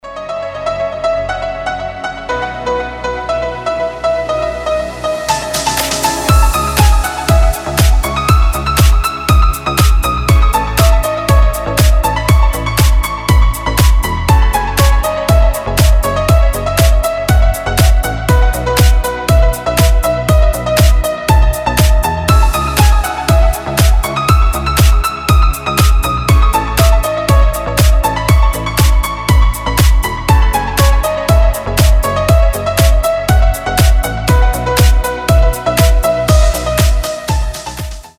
• Качество: 320, Stereo
deep house
без слов
красивая мелодия
пианино
Melodic
Красивый и мелодичный Deep House.